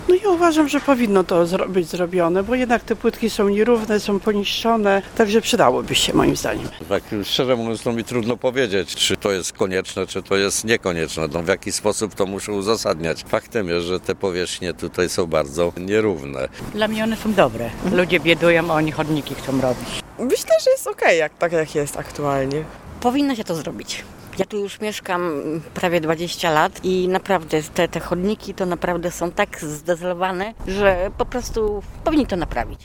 Zapytaliśmy mieszkańców, jakie mają zdanie na temat tej przebudowy: